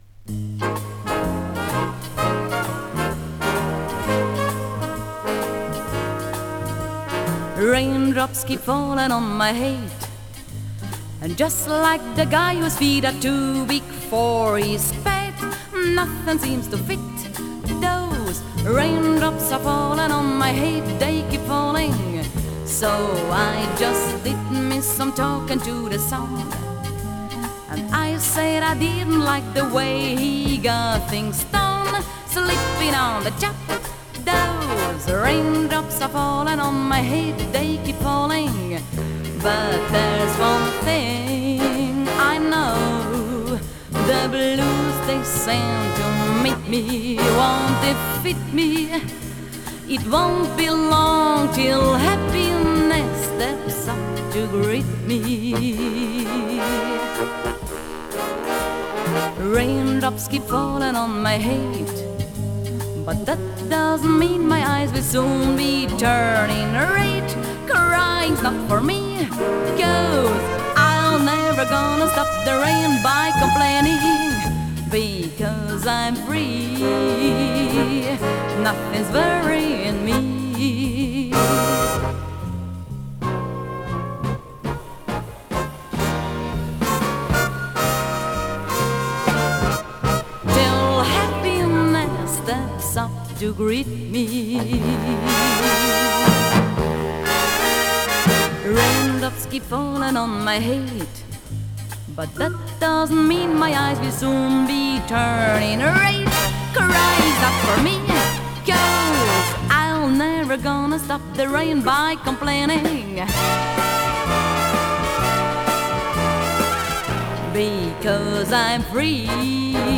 Recorded at The Brno  Radio Studio, 1970.